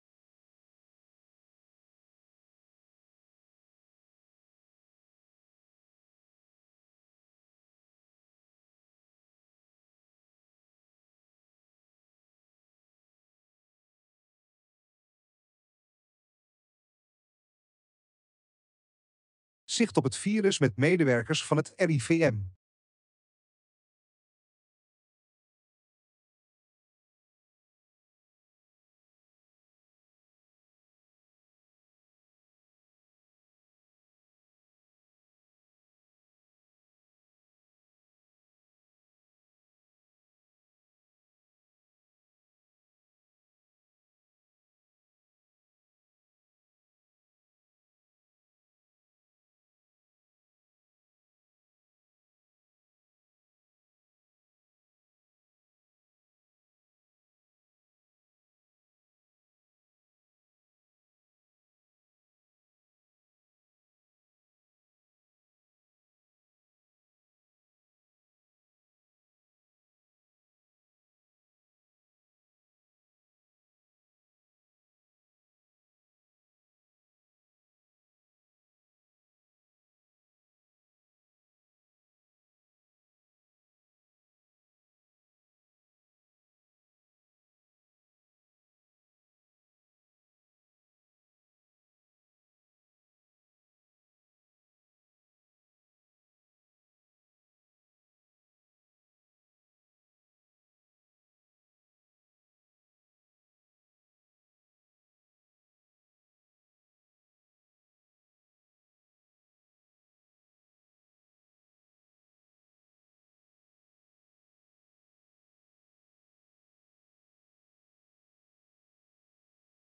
In deze video van het Rijksinstituut voor Volksgezondheid en Milieu, kortweg RIVM, gaan vijf medewerkers van het RIVM in op hoe ze zicht houden op het coronavirus.